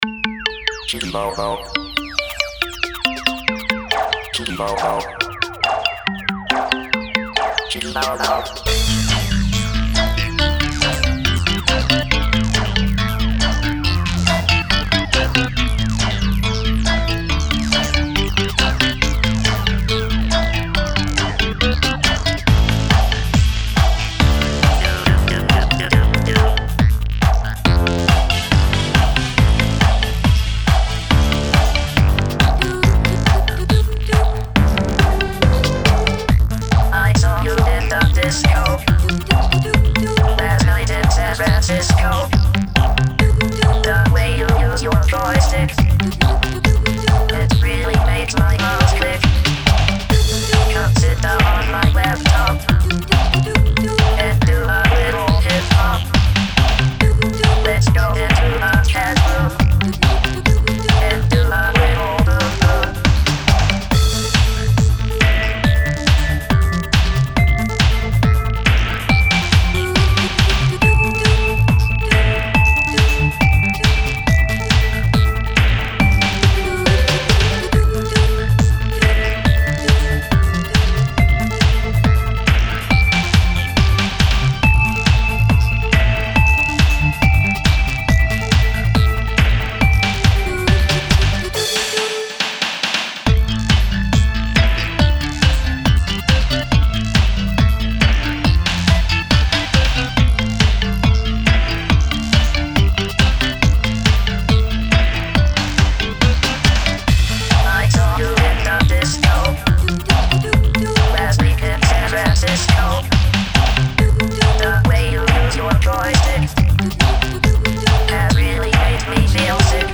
Сэмплы: всё своё кроме вокала